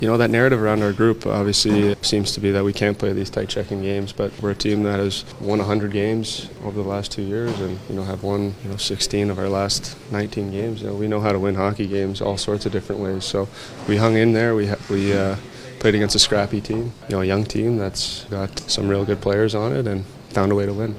Oilers captain Connor McDavid spoke to media and said this team has the ability to win ‘ugly’ 2-1 games or go toe to toe with some of the best offenses as well.